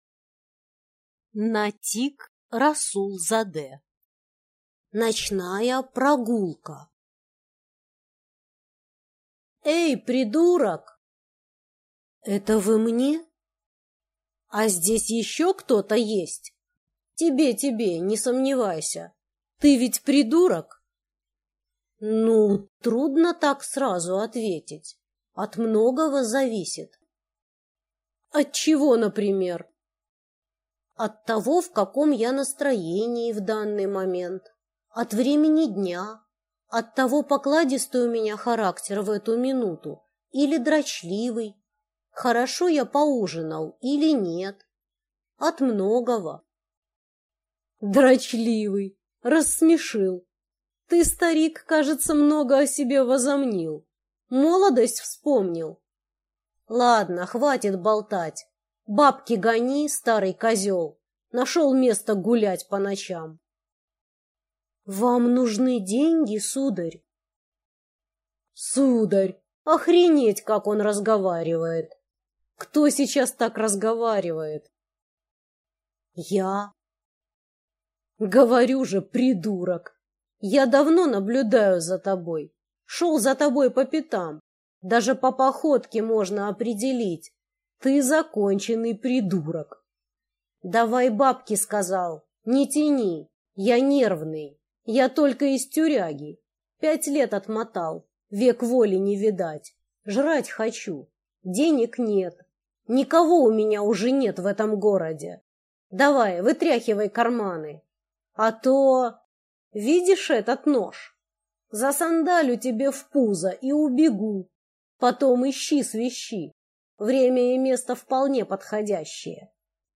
Аудиокнига Ночная прогулка | Библиотека аудиокниг